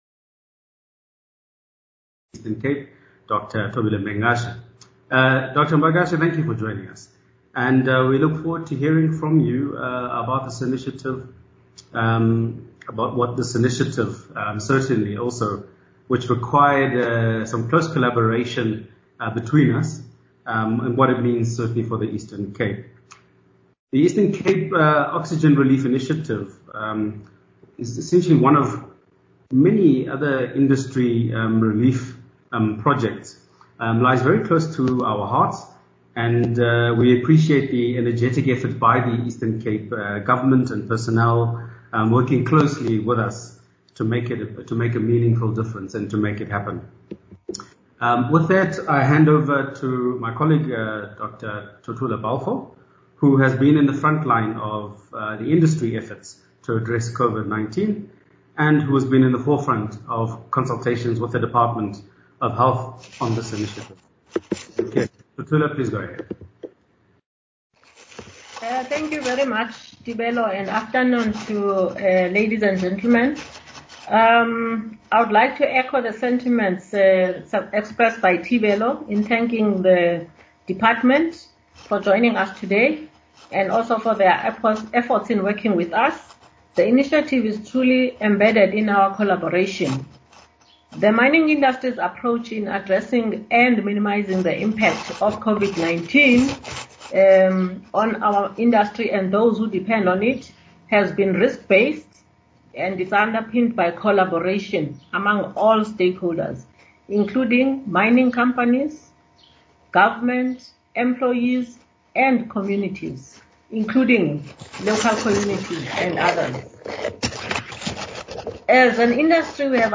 RECORDING: Joint online media briefing by the government of the Eastern Cape and Minerals Council South Africa on the oxygen relief initiative in the province, 16 September 2020
media-briefing-eastern-cape-intervention.mp3